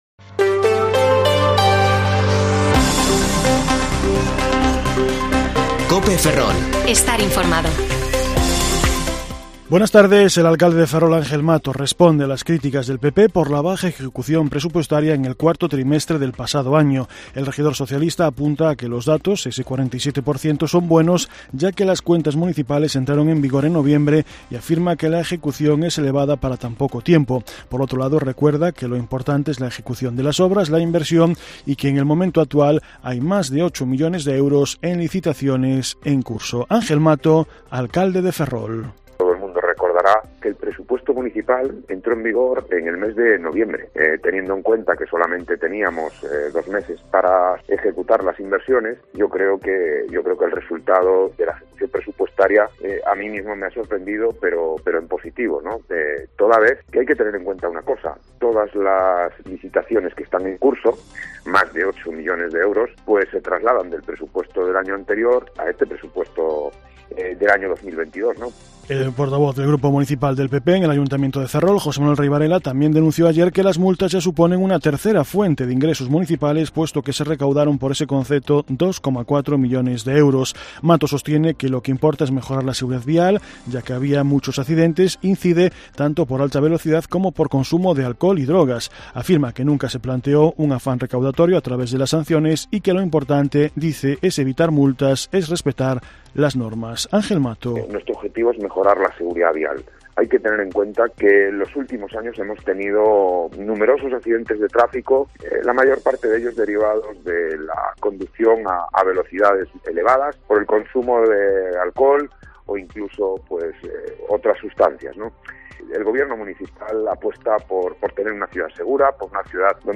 Informativo Mediodía COPE Ferrol 15/2/2022 (De 14,20 a 14,30 horas)